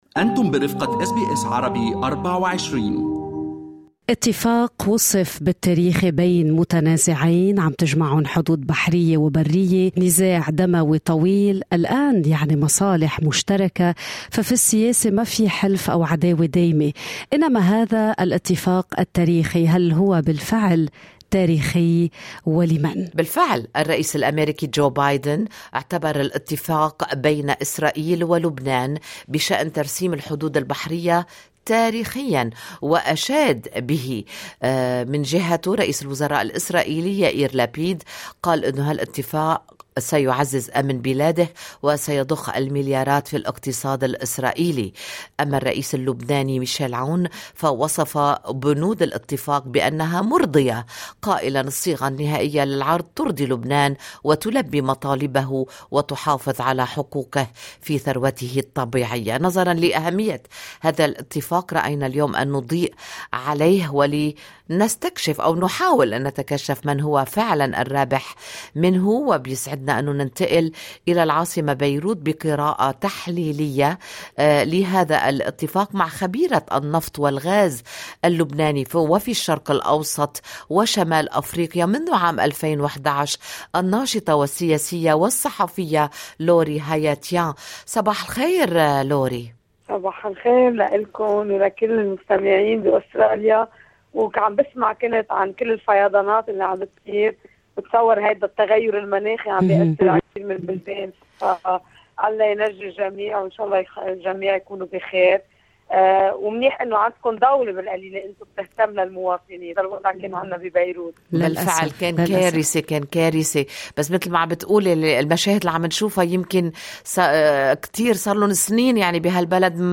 Lebanese oil & gas expert in the Middle East and North Africa